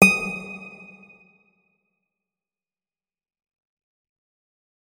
guitar
notes-63.ogg